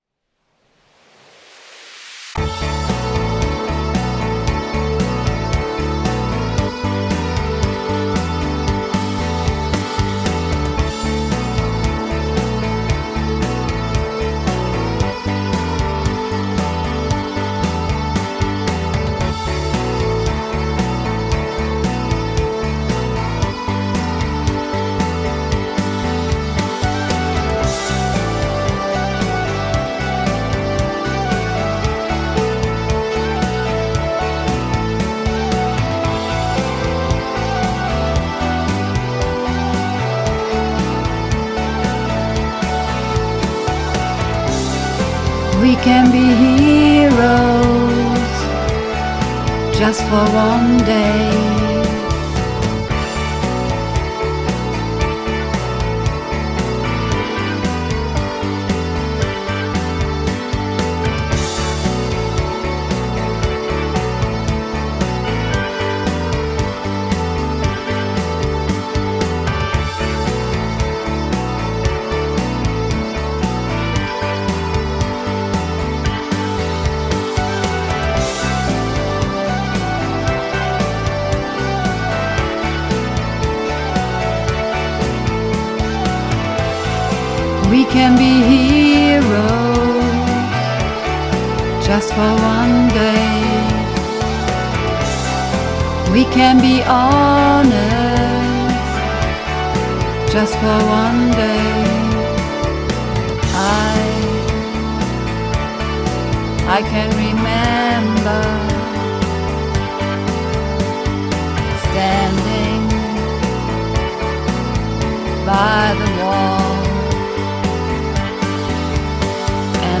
Übungsaufnahmen - Heroes
Heroes (Alt - "And the Shame..." - Hoch)
Heroes__2_Alt_Shame_Hoch.mp3